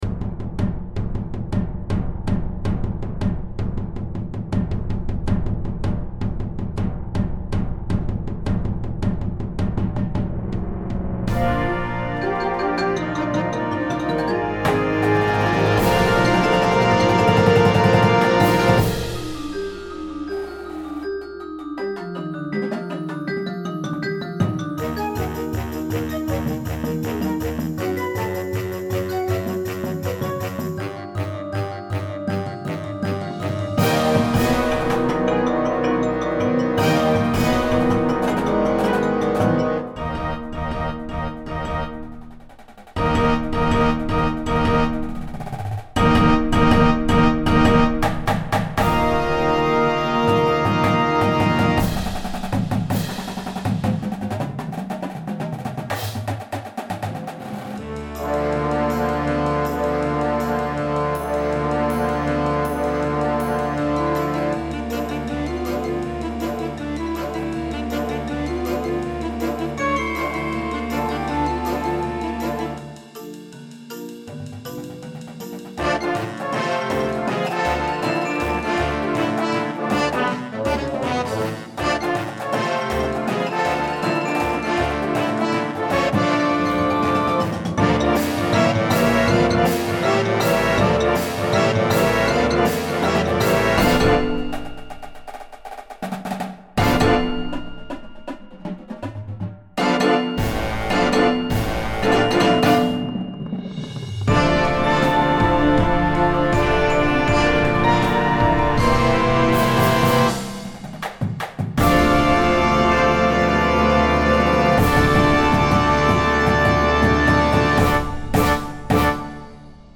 Winds and Percussion